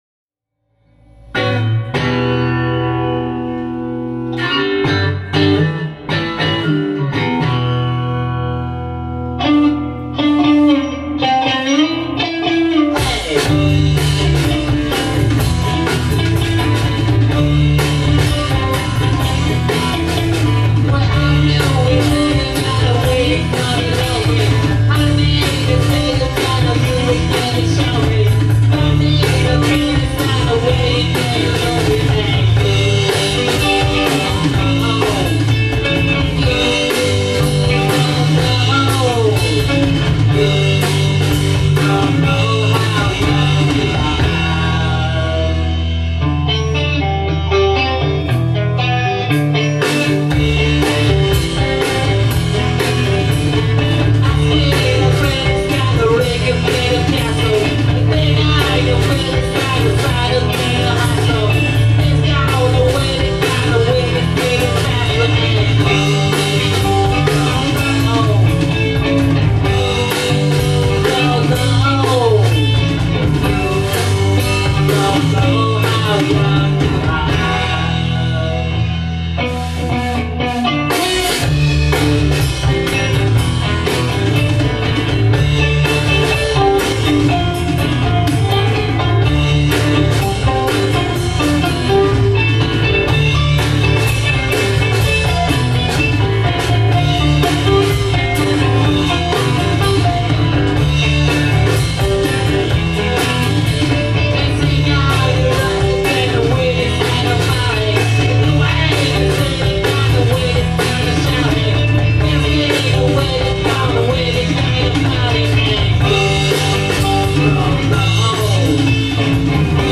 You Don’t Know 01 @ Studio Rat, June 14 2010